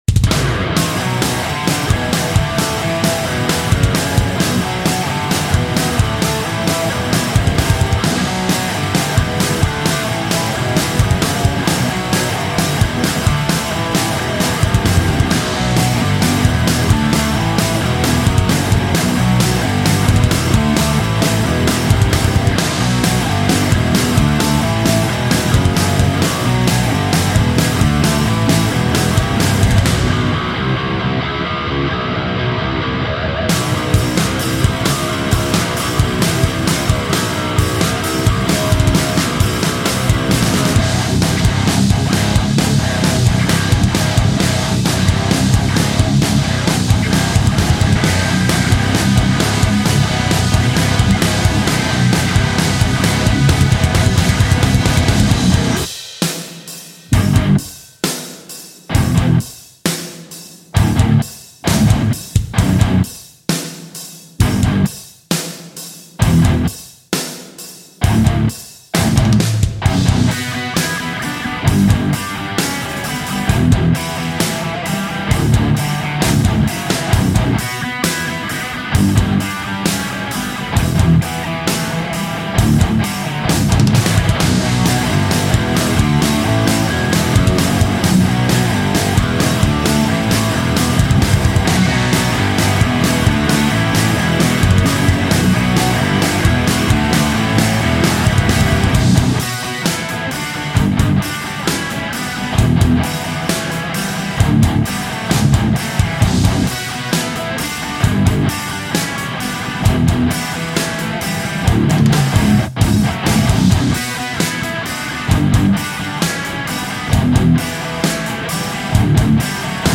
描述：摇滚乐|愤怒
Tag: 电吉他 贝司